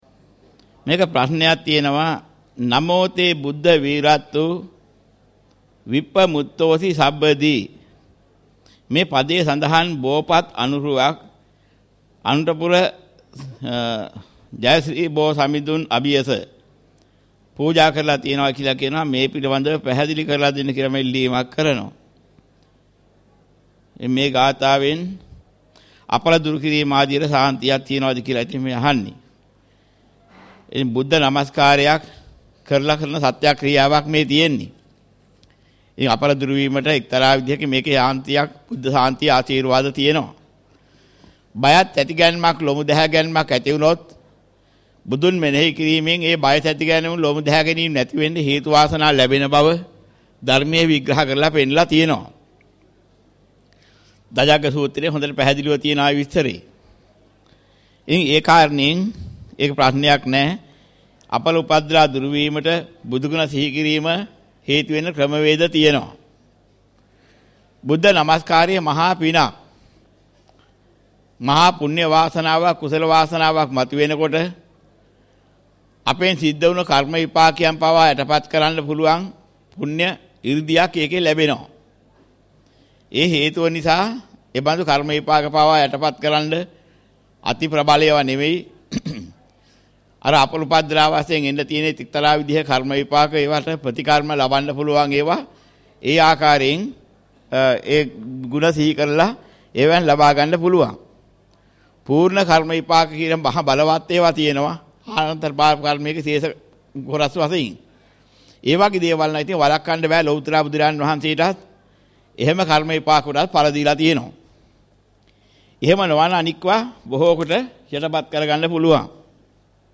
මෙම දේශනාවේ අඩංගු ගාථා හෝ සූත්‍ර කොටස්